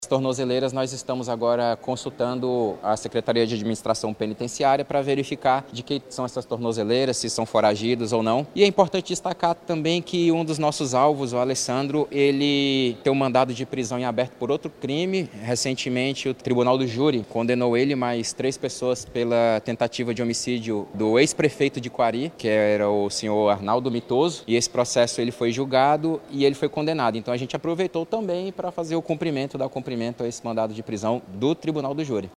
SONORA02_DELEGADO.mp3